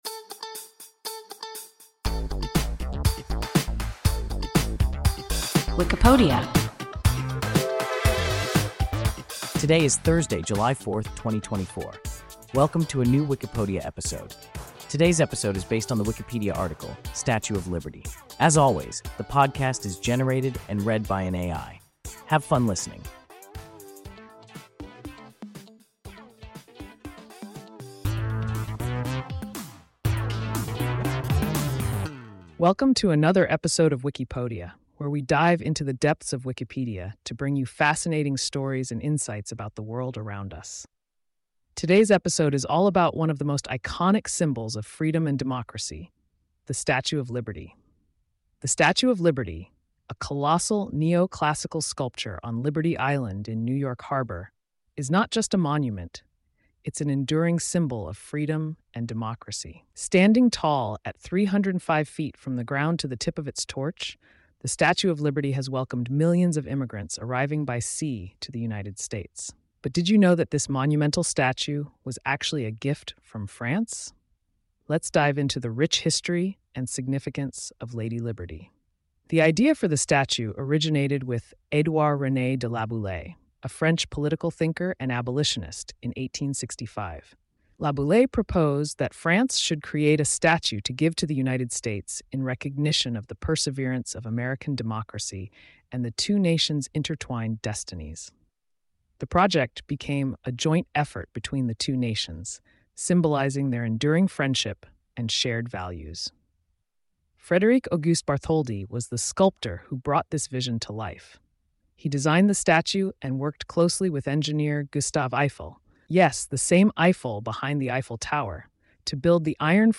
Wikipodia – an AI podcast